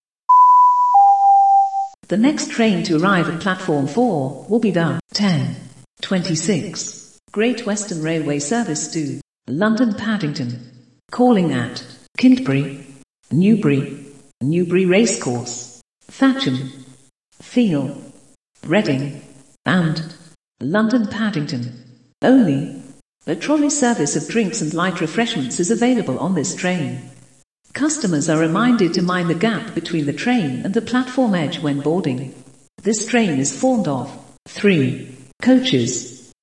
Software for model railway train departure announcements and background sound effects
• Real time chiming station clock that chimes the hours and every quarter hour
• Based on the current Atos – Anne system in use throughout the network